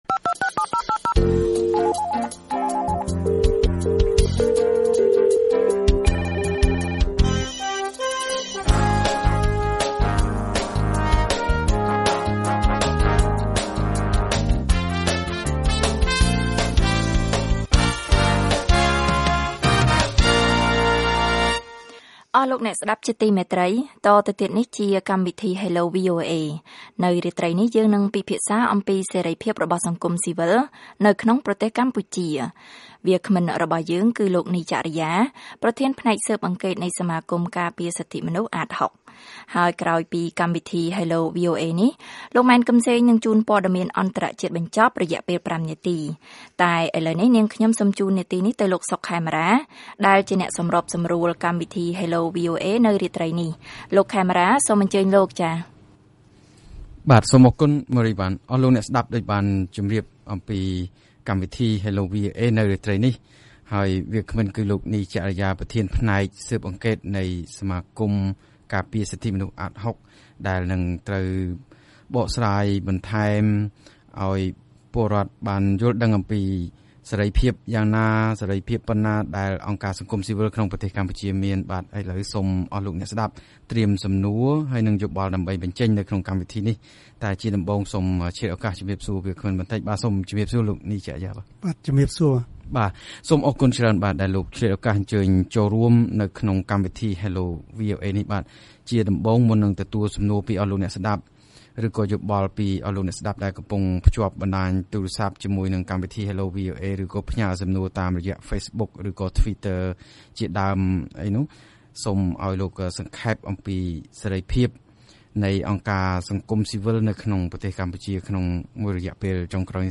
ដែលចូលរួមជាវាគ្មិនក្នុងកម្មវិធី Hello VOA កាលពីយប់ថ្ងៃច័ន្ទ។